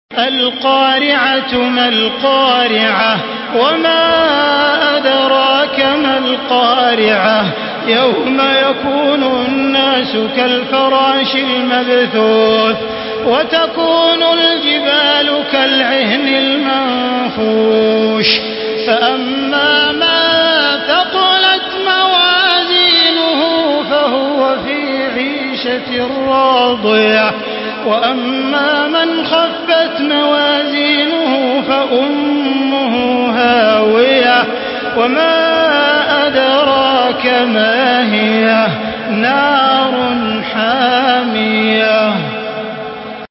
Surah القارعه MP3 by تراويح الحرم المكي 1435 in حفص عن عاصم narration.
مرتل حفص عن عاصم